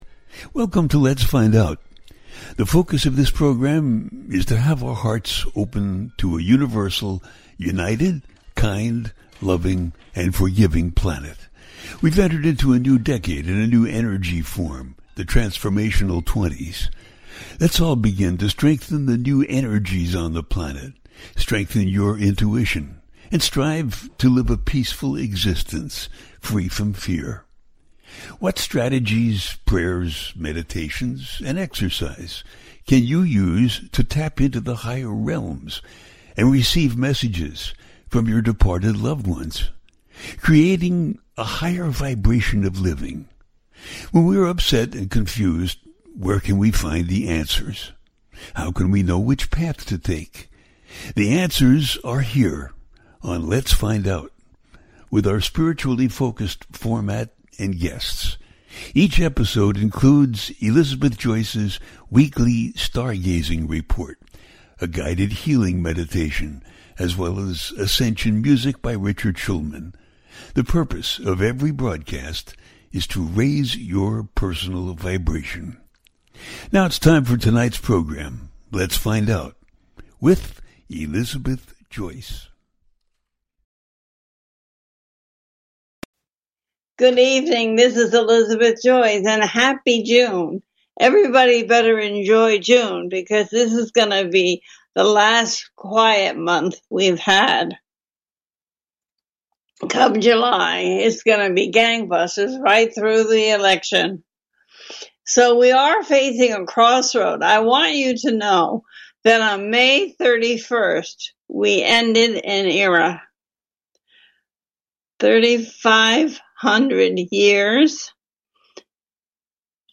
The Parade of Planets, New Gemini Moon, New Era, A teaching show